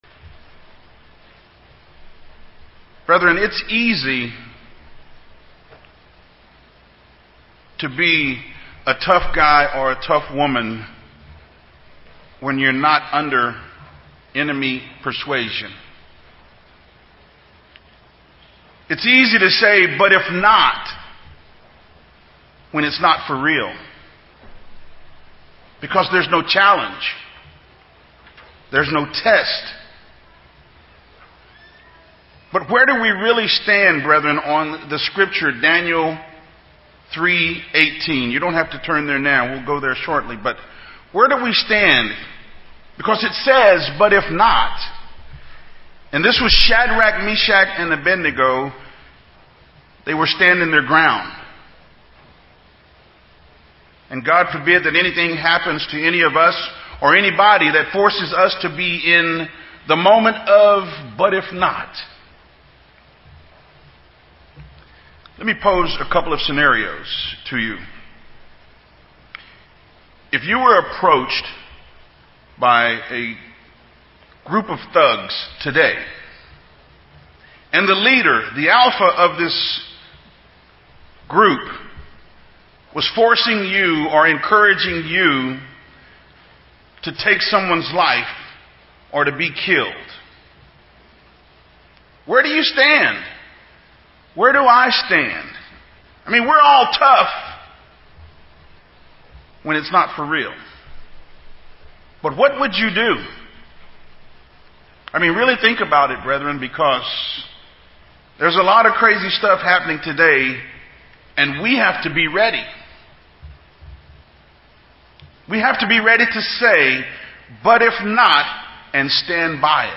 Given in San Antonio, TX